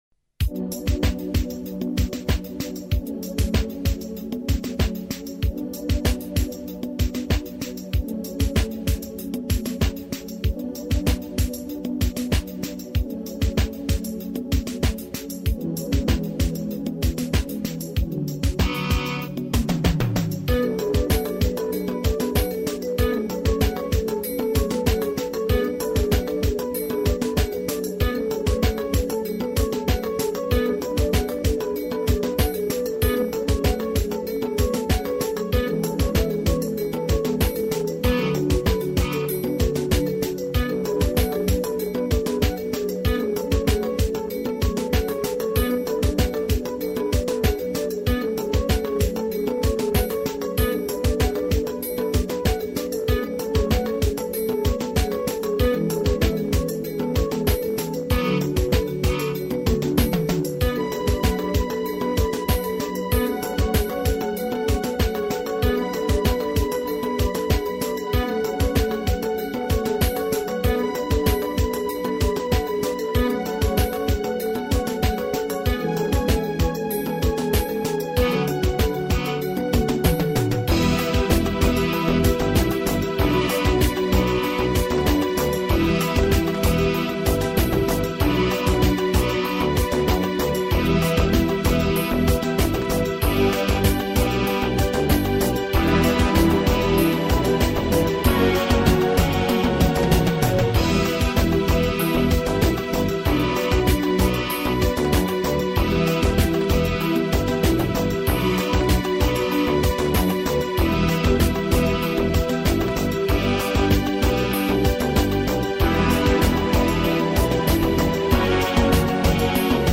минусовка версия 22011